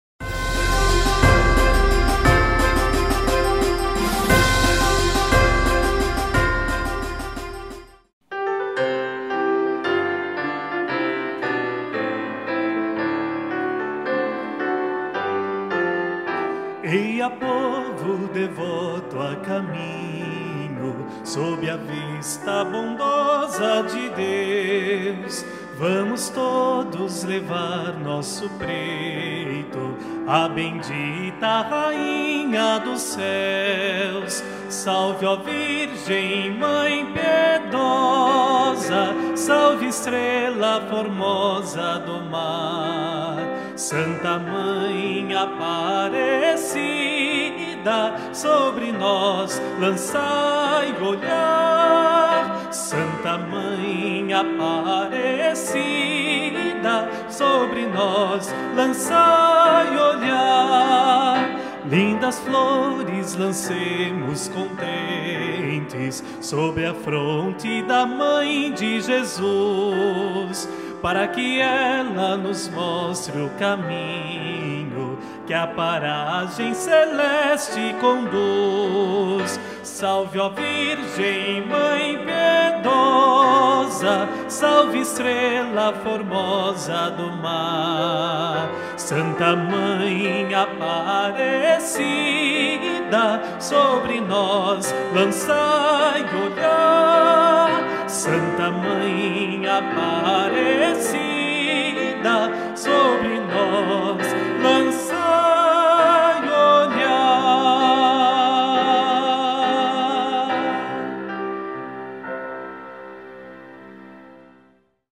Festa da Padroeira 2015: Hino a Nossa senhora Aparecida - Eia Povo
Letra/Música: Bento Ernesto Júnior – P. J. B. L.